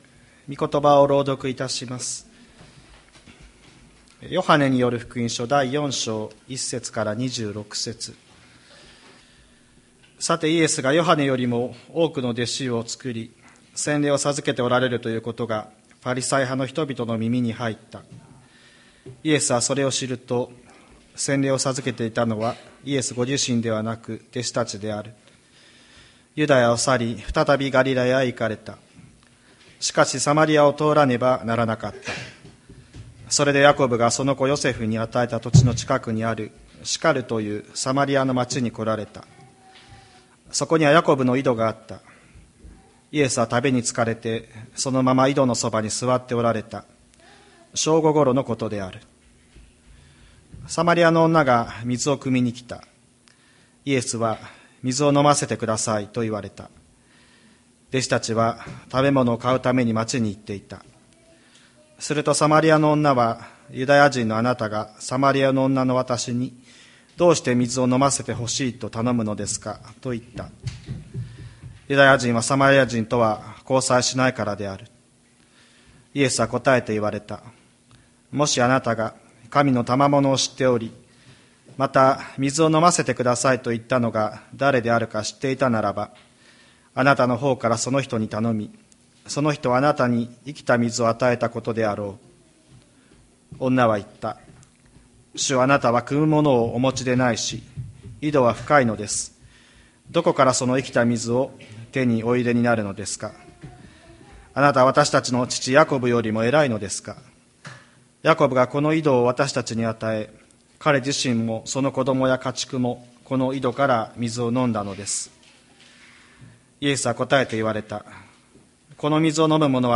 2023年08月20日朝の礼拝「だれを礼拝していますか」吹田市千里山のキリスト教会
千里山教会 2023年08月20日の礼拝メッセージ。